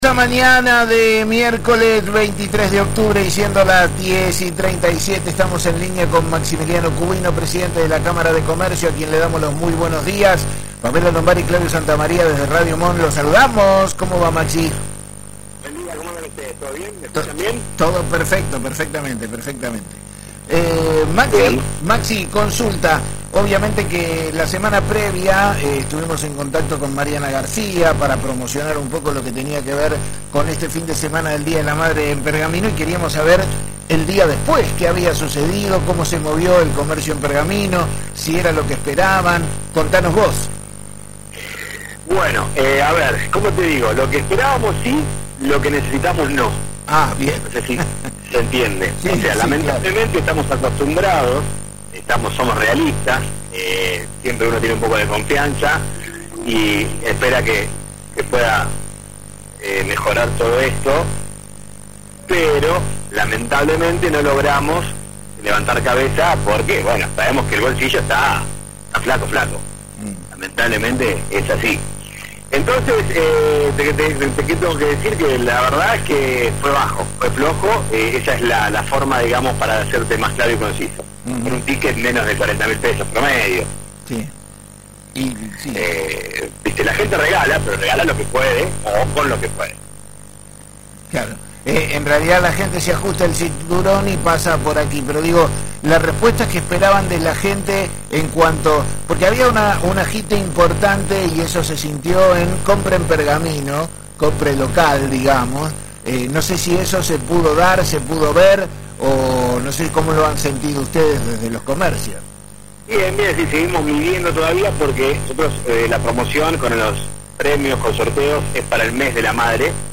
En una entrevista realizada esta mañana en el programa La Mañana de la Radio de Radio Mon Pergamino